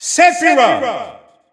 The announcer saying Sephiroth's name in French.
Sephiroth_French_Announcer_SSBU.wav